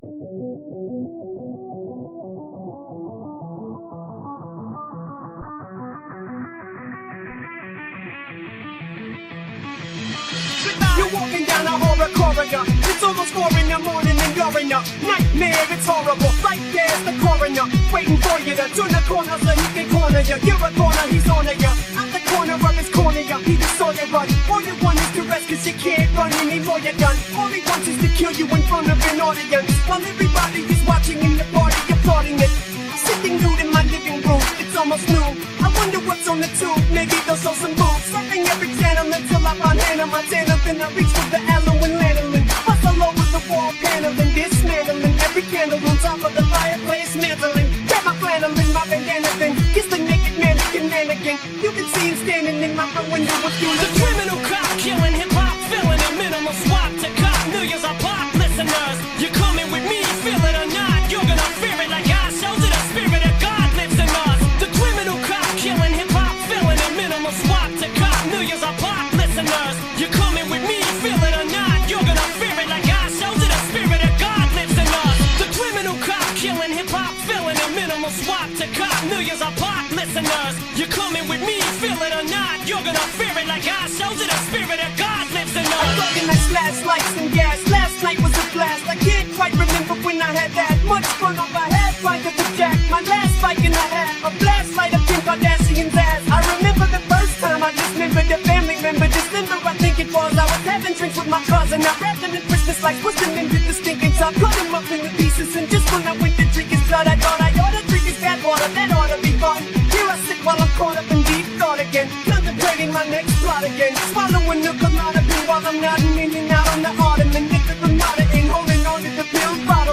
это ремикс